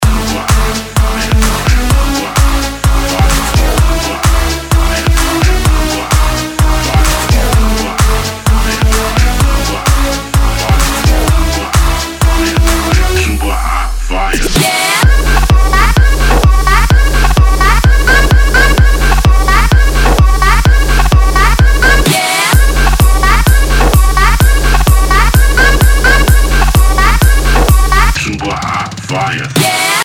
• Качество: 192, Stereo
Dutch House от сногсшибательного дуэта ди-джеев